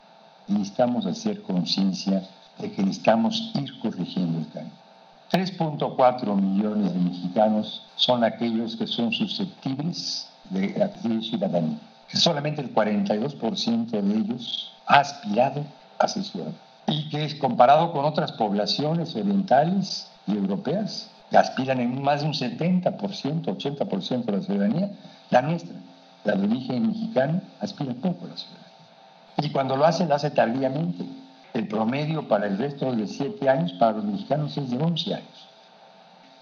En videoconferencia desde la sede de la UNAM en San Antonio, Texas, el rector Enrique Graue Wiechers indicó que hay 3.4 millones de mexicanos susceptibles de obtener la ciudadanía y a quienes se les debe dar confianza y apoyo para que aspiren a este derecho que redundará en su seguridad, mejoras laborales y les permitirá ejercer sus facultades políticas.
El rector Enrique Graue en la sede de la UNAM en San Antonio, Texas.